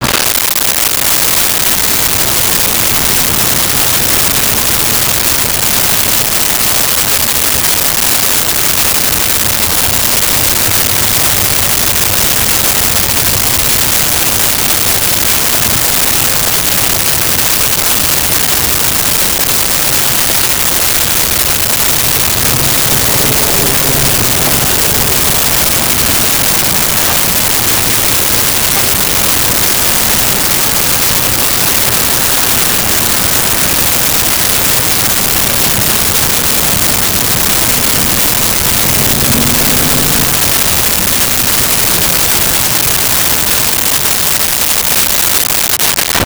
City Streets
City Streets.wav